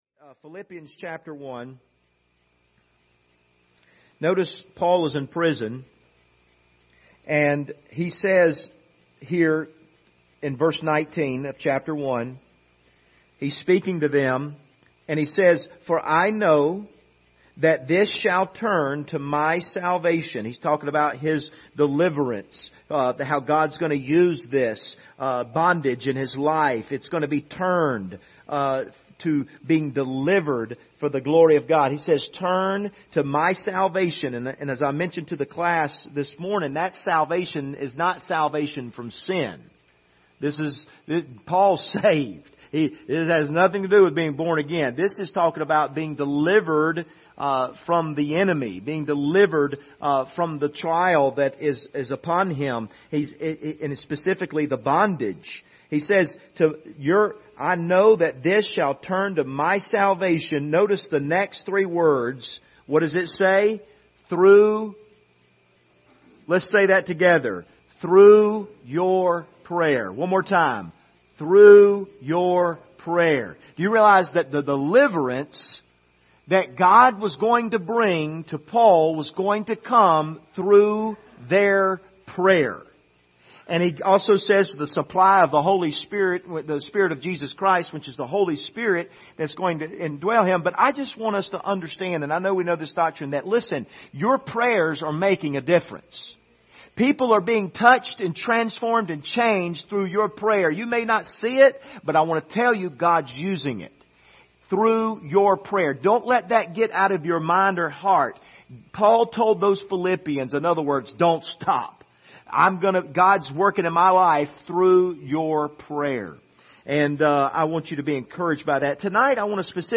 Philippians 1:19 Service Type: Sunday Evening Topics